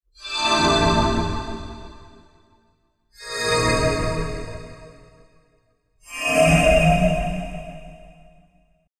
healing-spell.wav
sfx updates